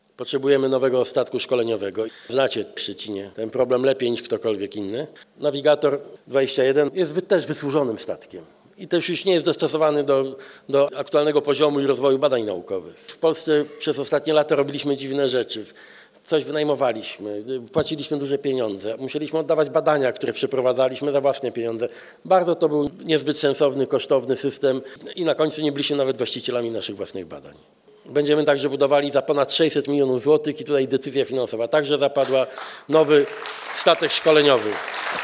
Podczas Międzynarodowego Kongresu Morskiego w Szczecinie premier Donald Tusk ogłosił, że zapadła decyzja o budowie nowego statku szkoleniowego dla Politechniki Morskiej. Nowa jednostka ma być naszpikowana nowoczesną technologią – w tym robotyką podwodną oraz pełną automatyką badawczą.
W trakcie wystąpienia premier nie tylko ogłosił decyzję inwestycyjną, ale również skrytykował dotychczasowy sposób organizowania badań morskich w Polsce: